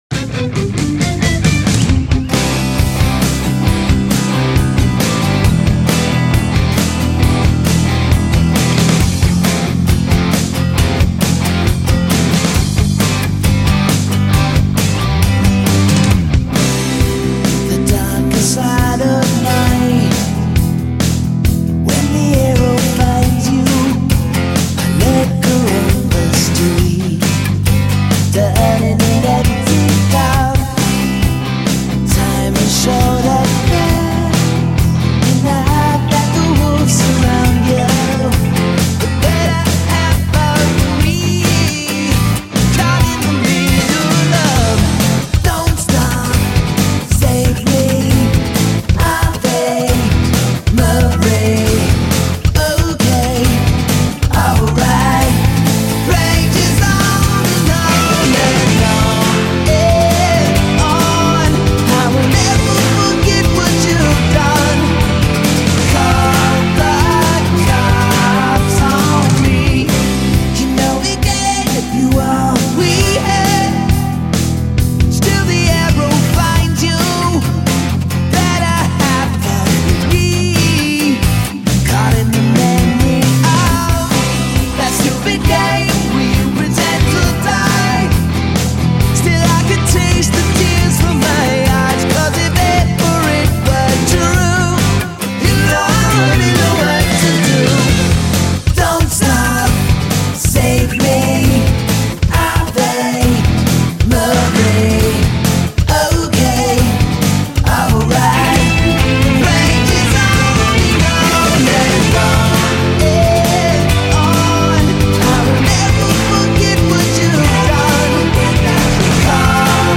such as the guitar solo, intercut with vocals